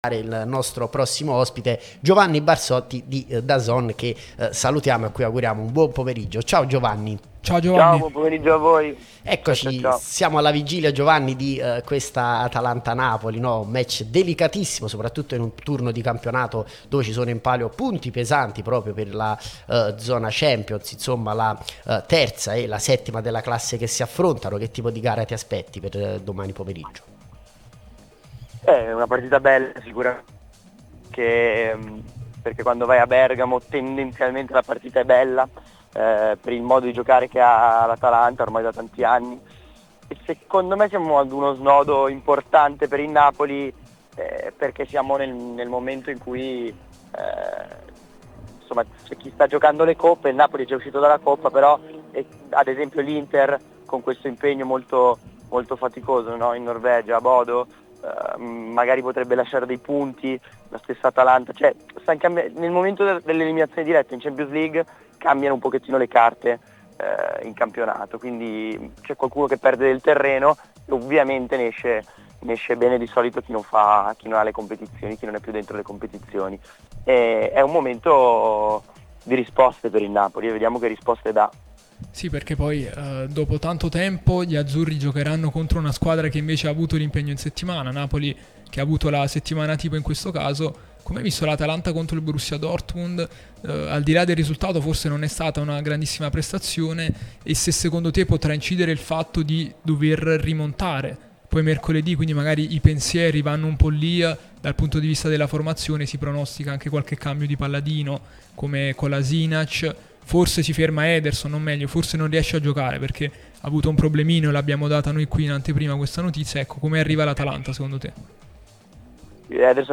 giornalista Dazn